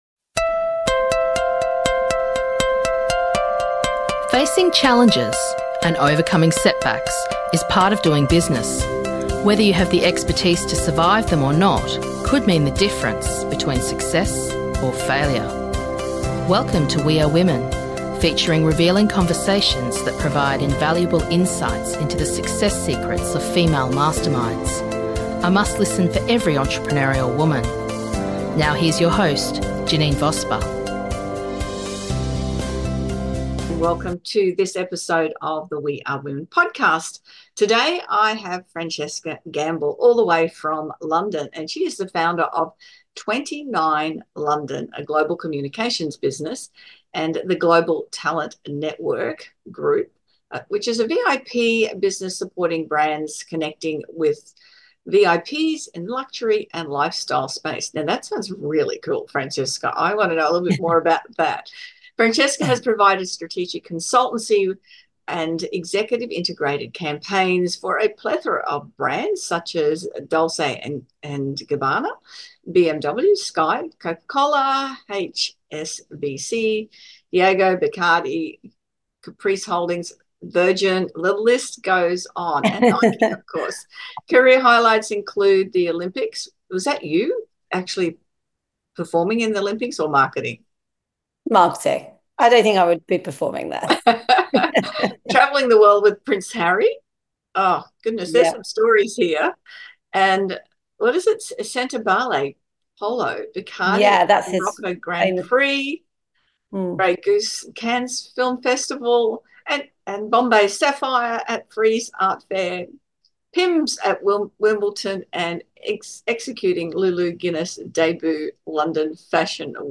We Are Women Podcast features revealing conversations that pro-vide invaluable insights into the secrets of success of female masterminds.